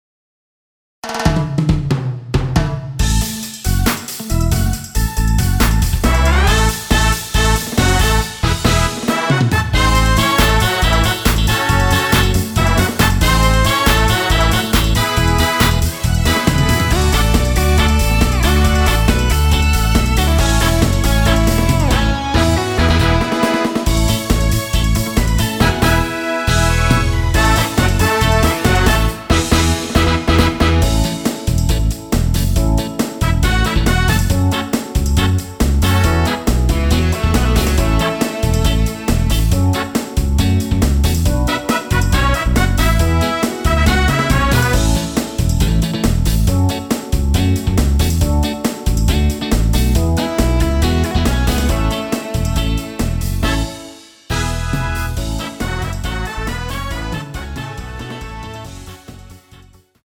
원키에서(-1)내린 MR입니다.
◈ 곡명 옆 (-1)은 반음 내림, (+1)은 반음 올림 입니다.
앞부분30초, 뒷부분30초씩 편집해서 올려 드리고 있습니다.
중간에 음이 끈어지고 다시 나오는 이유는